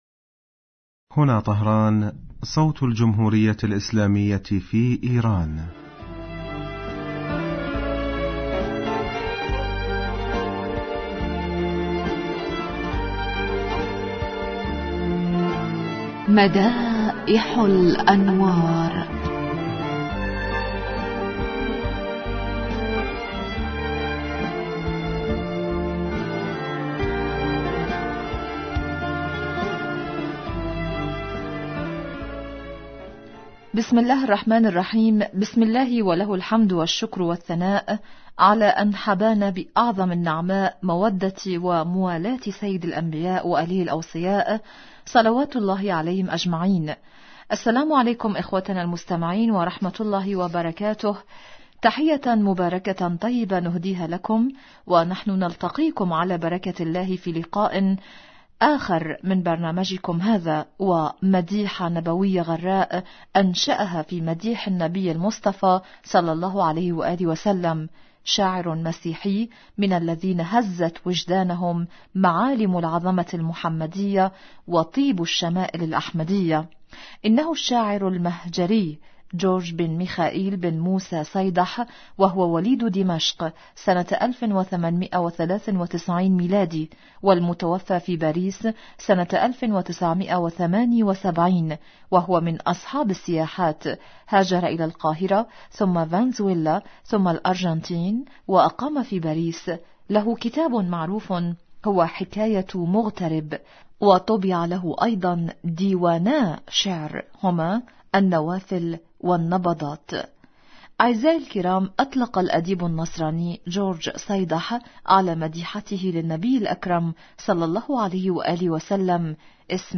إذاعة طهران- مدائح الانوار: الحلقة 463